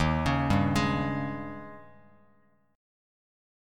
D#11 chord